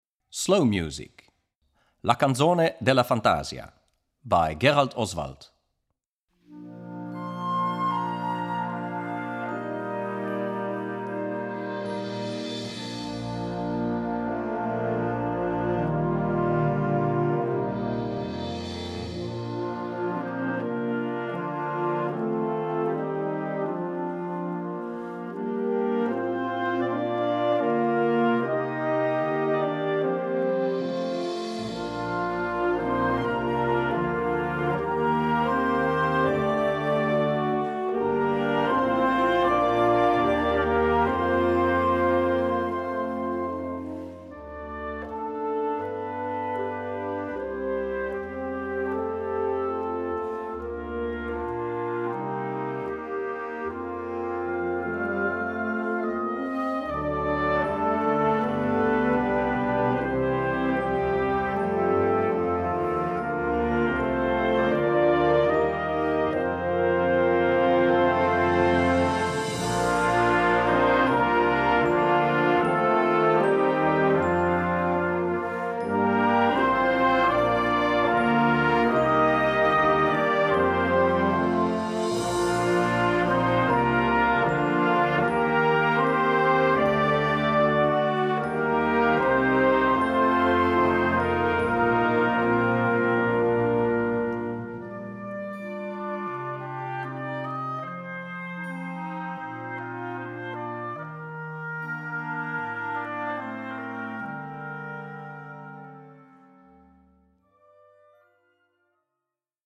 Gattung: Konzertwerk
Besetzung: Blasorchester
behutsame Instrumentierung